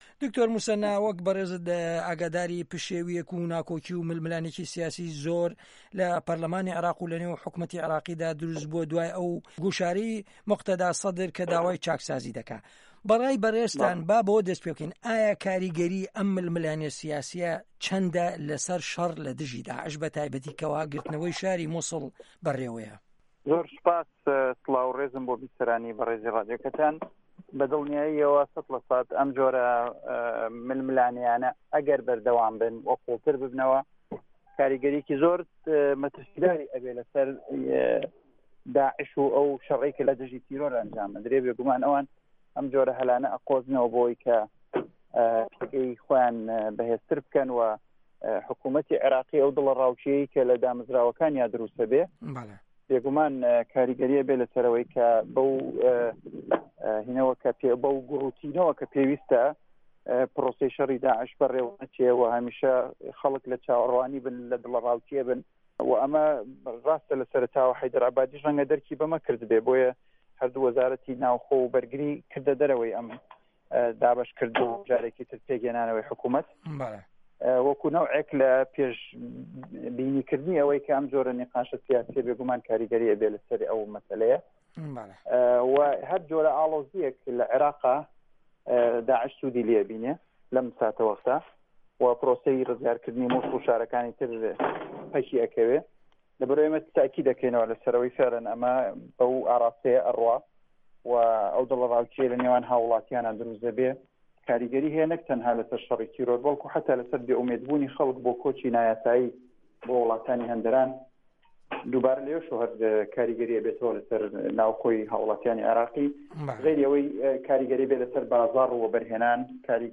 عێراق - گفتوگۆکان
وتووێژ لەگەڵ موسەنا ئەمین